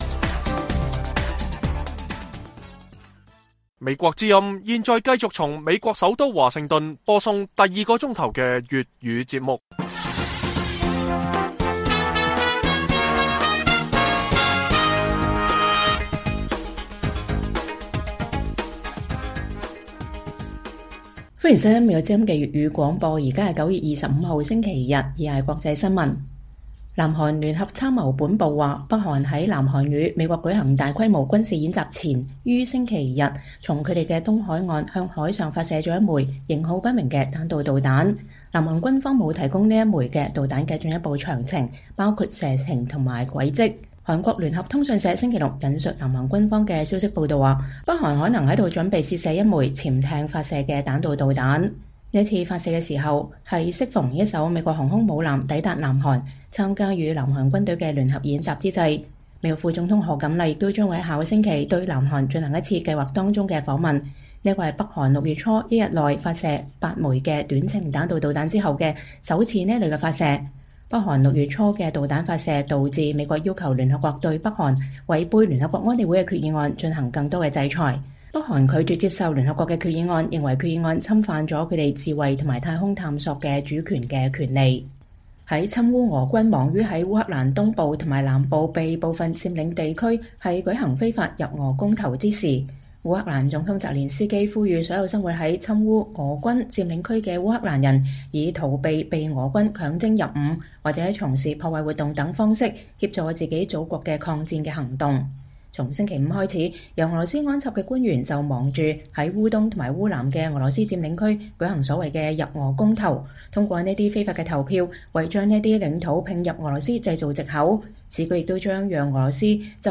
粵語新聞 晚上10-11點：北韓在美國副總統賀錦麗訪問前發射彈道導彈